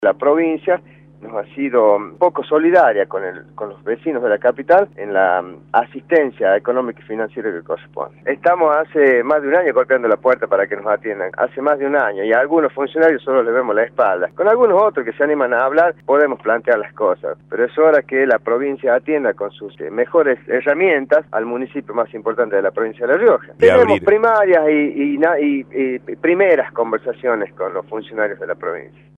Armando Molina, viceintendente, por Radio La Red